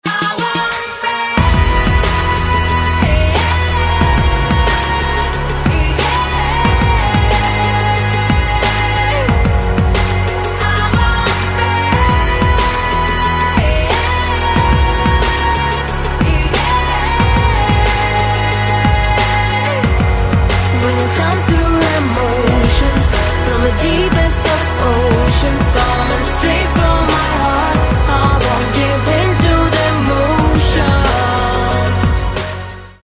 • Качество: 320, Stereo
поп
громкие
мотивирующие